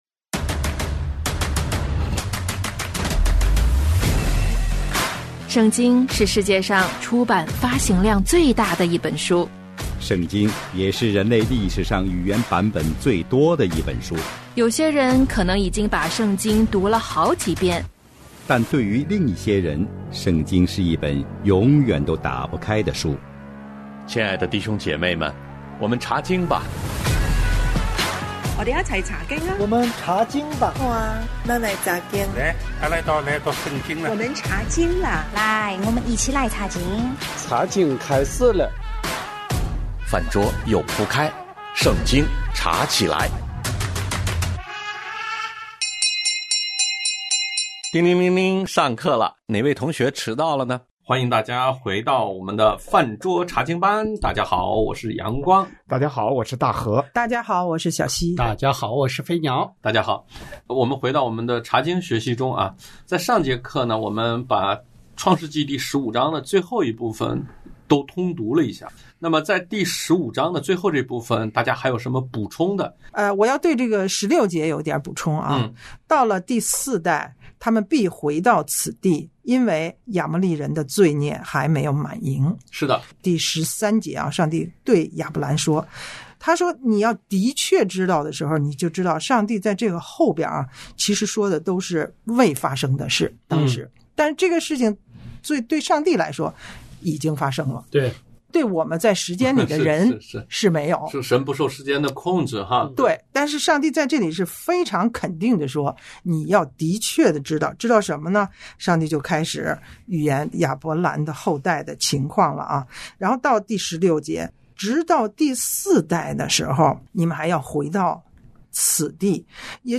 纳妾：这节课大家先总结了15章的内容，再次探讨了因信称义的教义，接着开启了16章的讨论，讲到撒莱打算为丈夫纳妾。